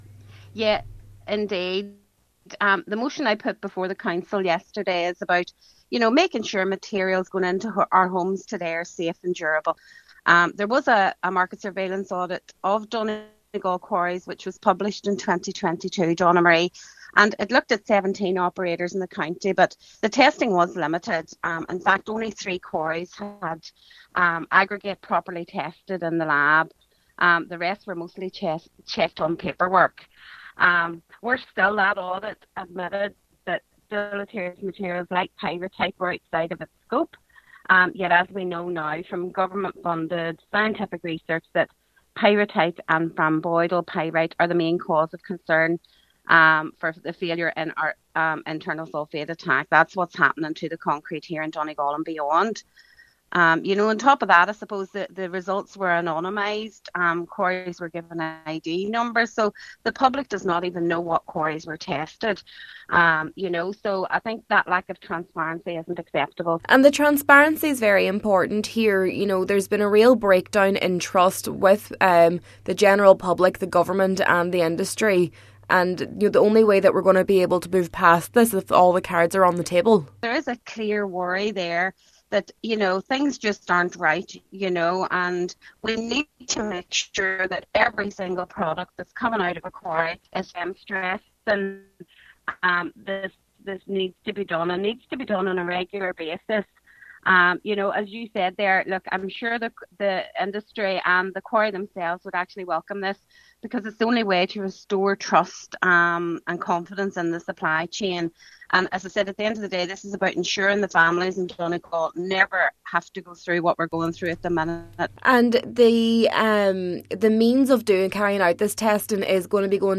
An audit was carried out a number of years ago, however, Cllr Beard says it has quickly become outdated: